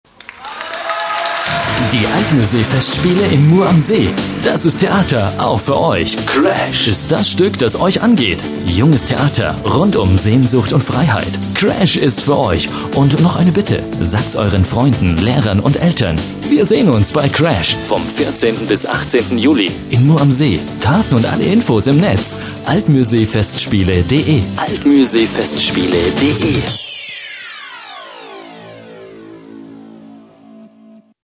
Sprecher deutsch für Radiowerbung, Tv-Werbung, Pc-Spiele, Industriefilme...
Sprechprobe: Sonstiges (Muttersprache):
german voice over talent